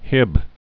(hĭb)